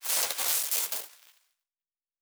pgs/Assets/Audio/Sci-Fi Sounds/Electric/Spark 10.wav at 7452e70b8c5ad2f7daae623e1a952eb18c9caab4
Spark 10.wav